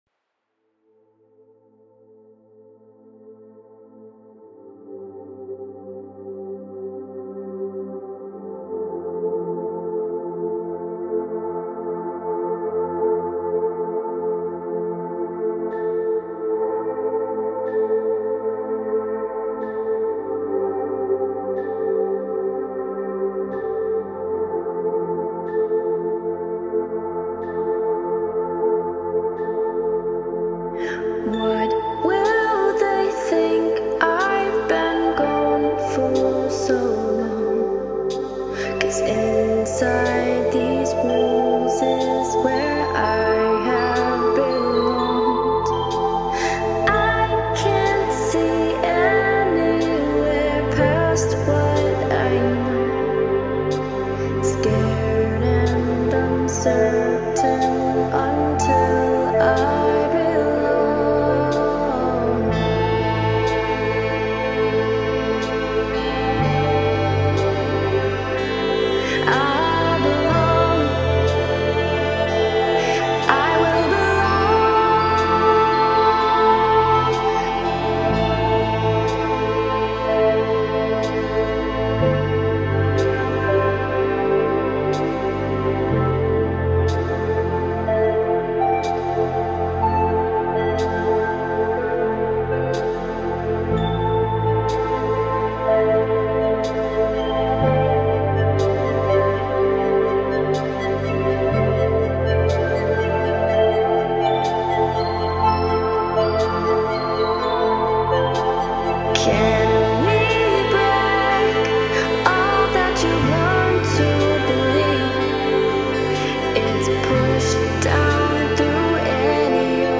more chill version